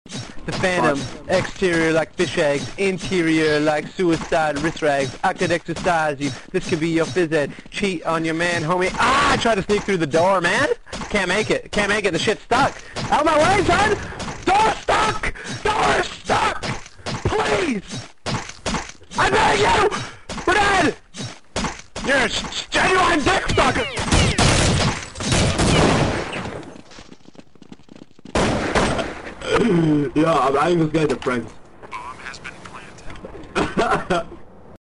Door Stuck Door Stuck Full